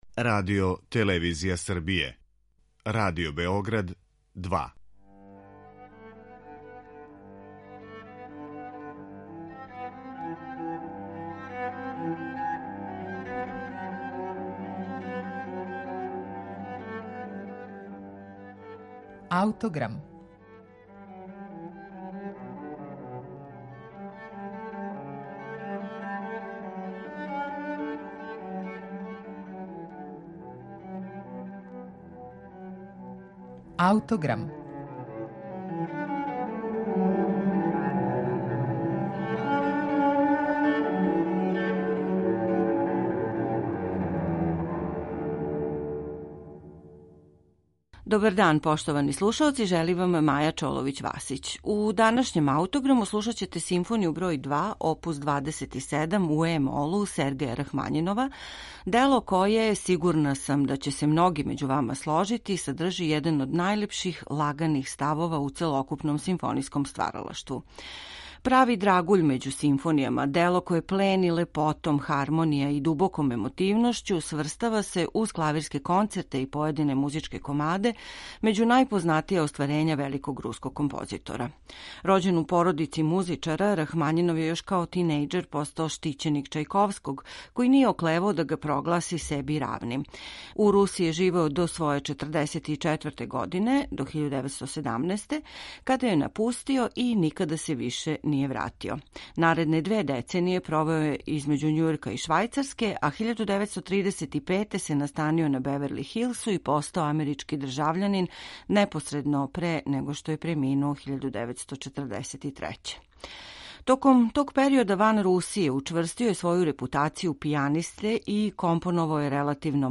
Државни симфонијски оркестар некадашњег Совјетског савеза под диригентским вођством Јевгенија Светланова
Симфонија бр 2, прави драгуљ међу симфонијама, дело великих размера и раскошне и богате оркестарске звучности, које плени лепотом хармонија и дубоком емотивношћу, сврстава се (уз клавирске концерте, и поједине музичке комаде) међу најпознатија остварења великог руског композитора.
III став, Adagio, издваја се интензитетом осећања које код слушаоца изазива.